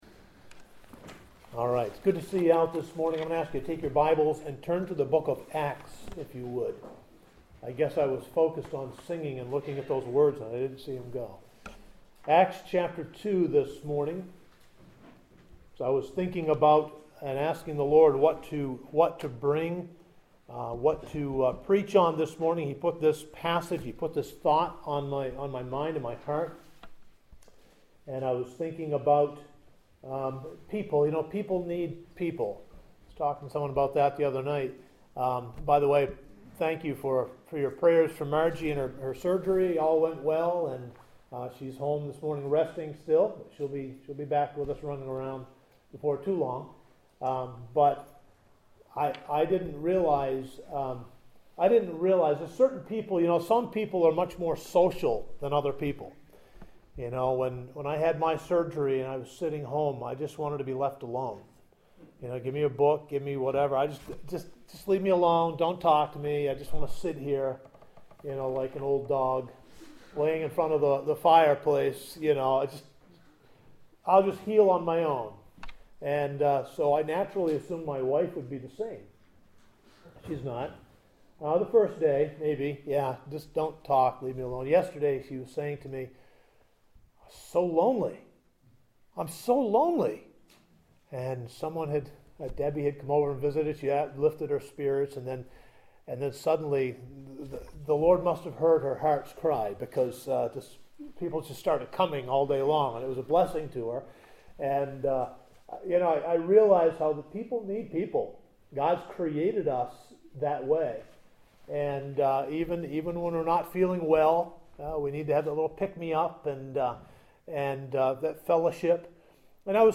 Return to Sermon Archive